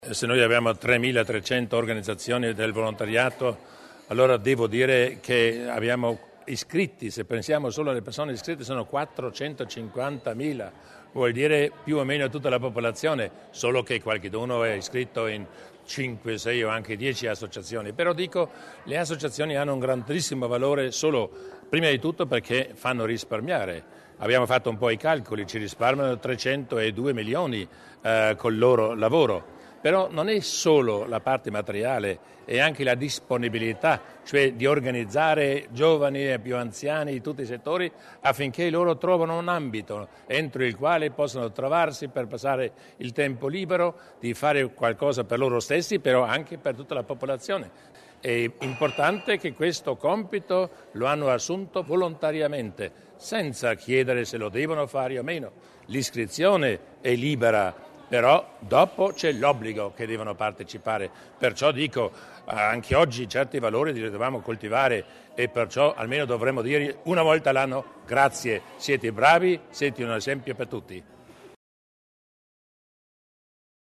Il Presidente Durnwalder sull’importanza del volontariato
Sanità / Sociale | 04.12.2010 | 21:49 Premiati dal presidente Durnwalder sette volontari altoatesini Si è svolta nel tardo pomeriggio di oggi (sabato 4 dicembre), in occasione della Giornata Internazionale del Volontariato, la cerimonia di premiazione di sette volontari altoatesini che si sono particolarmente distinti nei loro settori di attività.